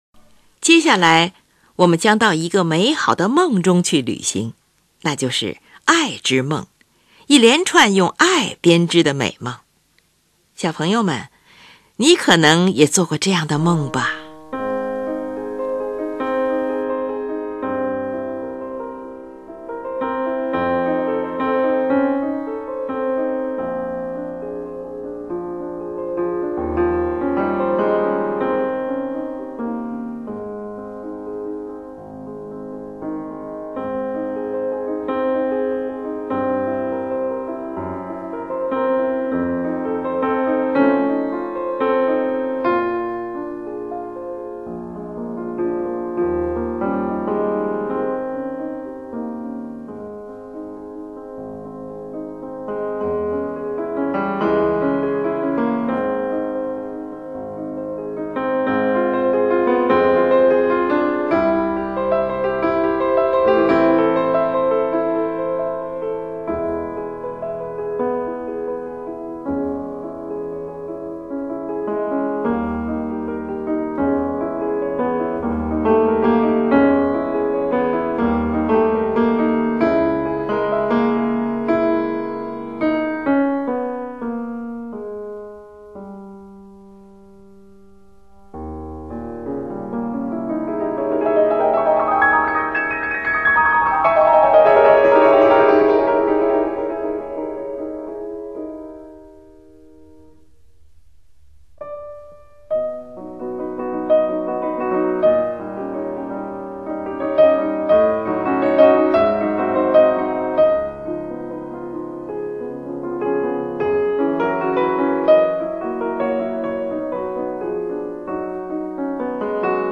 三首作品都是运用夜曲体裁。
音乐共分成五段，一段比一段显得浓烈而深沉。第三、四段热情洋溢，使音乐达到高潮，再回到开头——尽情地歌唱。